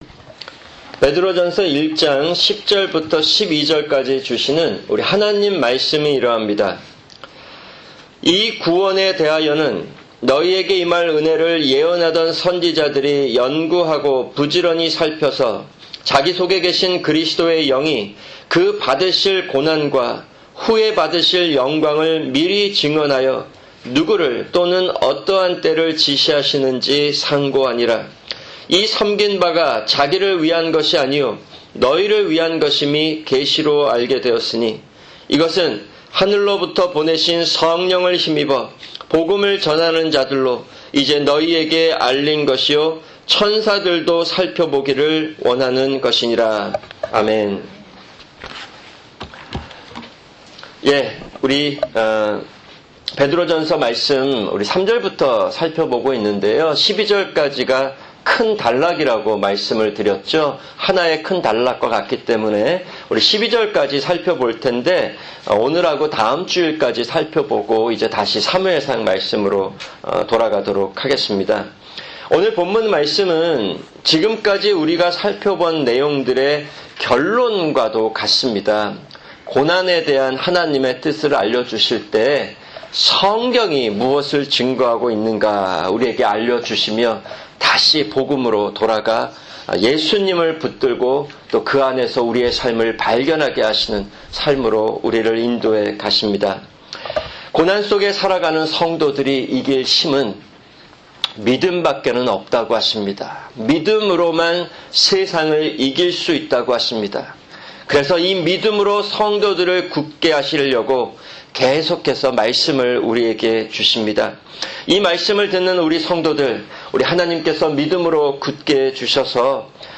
[주일 설교] 베드로전서1:10-12(2)